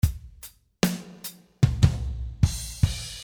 This track is in 75 bpm. In total length of 2:30 minutes and its contains 21 real drum loops. Its start with loops with ride cymbals playing very soft. Very big and fat snare with long reverb, use it for ballad song style.